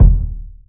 Kick21.wav